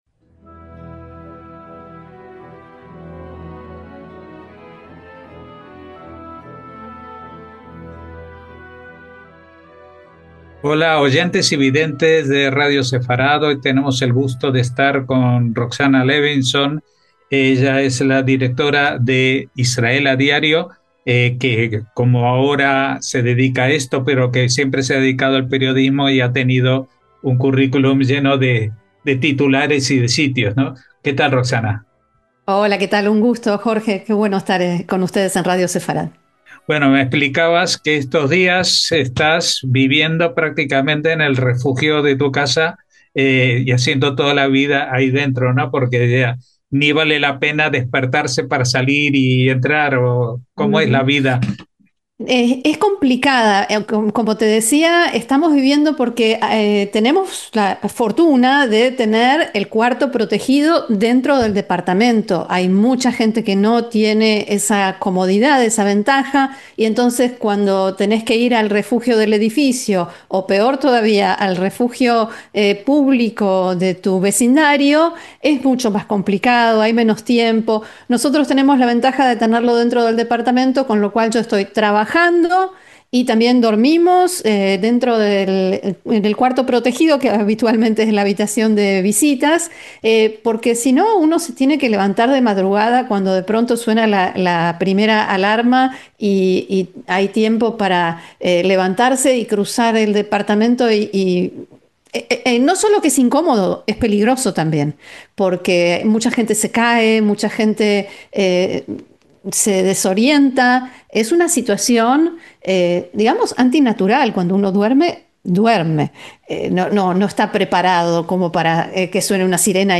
Nos atiende desde el cuarto protegido de su vivienda, del que raramente sale por si suenan las sirenas que anticipan un ataque de misiles de Irán, Líbano o ambos, algo muy habitual y que condiciona la vida rutinaria: ¿cuándo podré ducharme sin tener que salir desnudo y enjabonado a refugiarme?